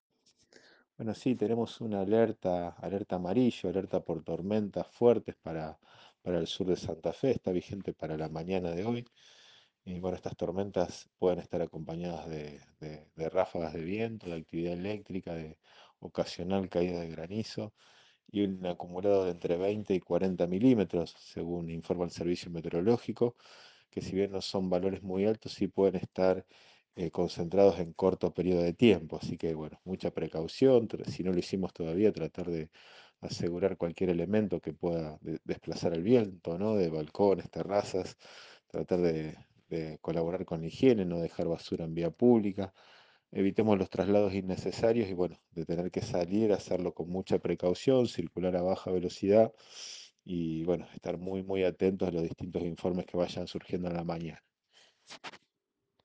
Gonzalo Ratner, secretario de Defensa Civil de la Municipalidad, habló en Radioinforme 3, por Cadena 3 Rosario, e informó: “Tenemos un alerta amarillo vigente. Puede haber vientos, actividad eléctrica, ocasional caída de granizo, acumulado de entre 20 y 40 milímetros de lluvia en cortos períodos de tiempo”.